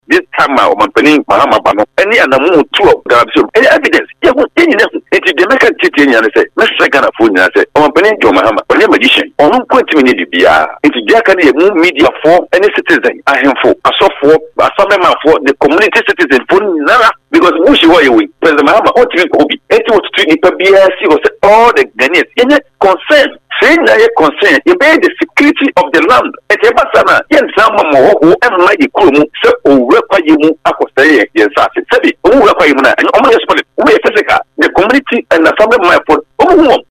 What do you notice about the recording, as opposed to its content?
Speaking on Radio1’s morning show, he emphasized that the battle against galamsey should not be left to the government alone.